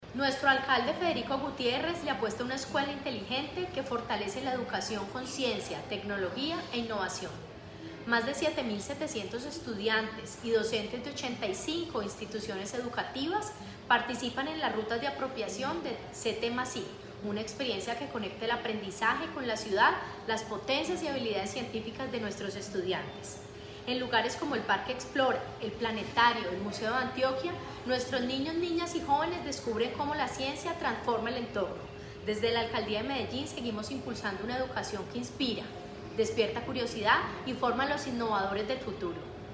Declaraciones-secretaria-de-Educacion-Carolina-Franco-Giraldo.mp3